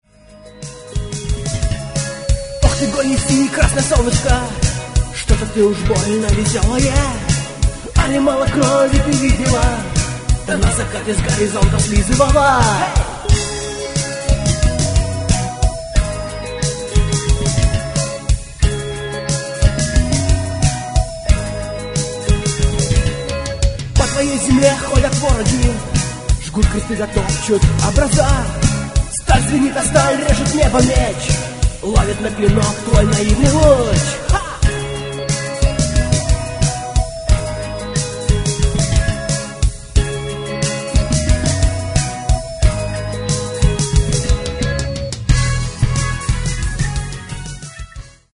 Клавиши, гитара, перкуссия, вокал
фрагмент (284 k) - mono, 48 kbps, 44 kHz